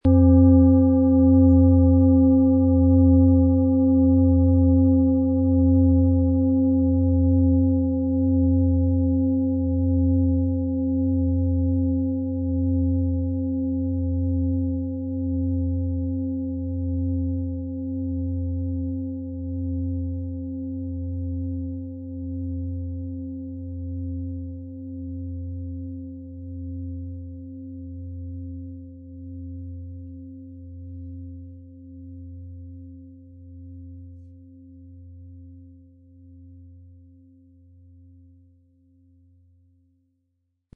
XXXL-Fußreflexzonen-Klangschale - Weite Klänge für tiefe Erdung
Ein sanfter Schlag genügt, und die Schale entfaltet tiefe Töne, die dich erden und entspannen.
So trägt sie eine einzigartige Klangsignatur in sich - lebendig, authentisch und voller Charakter.
MaterialBronze